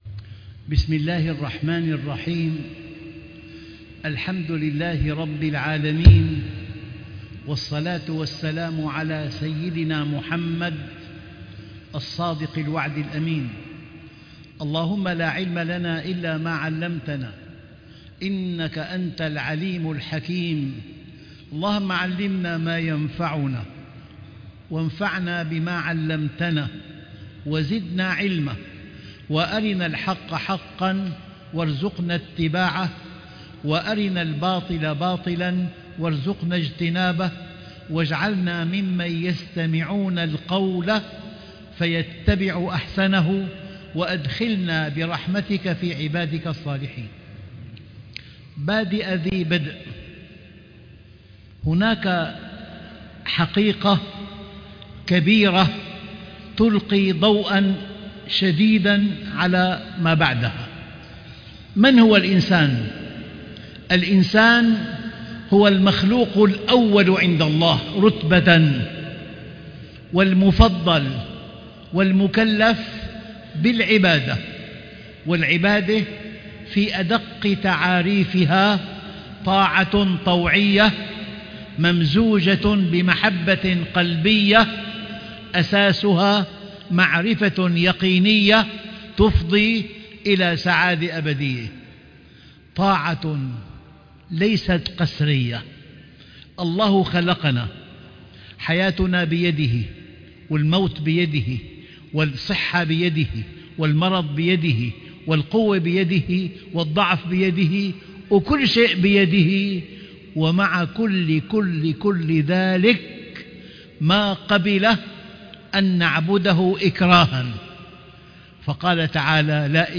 علاقة المؤمن بالله عزَّ وجل -الجزائر - المحاضرة 24 - الشيخ محمد راتب النابلسي